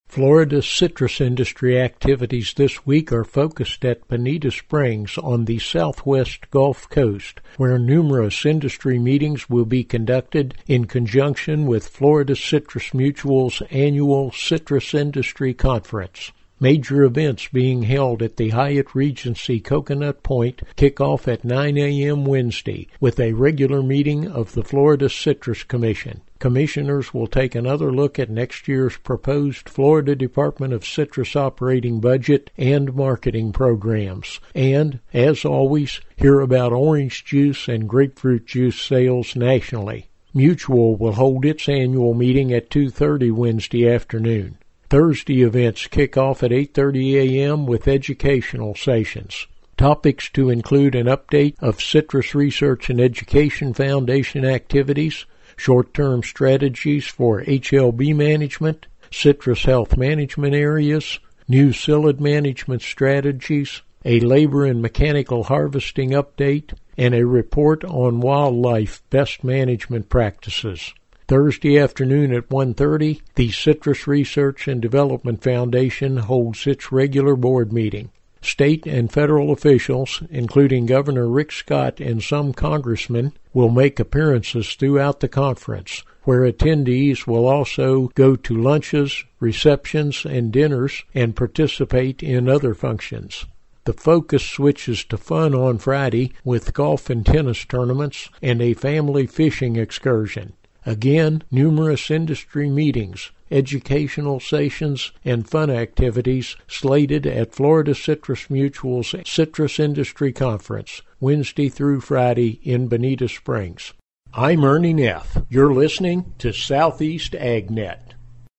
Numerous Florida citrus industry meetings and an educational session are on tap this week in conjunction with Florida Citrus Mutual’s Citrus Industry Conference. Hear details in this report.